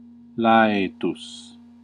Ääntäminen
France: IPA: /kɔ̃.tɑ̃/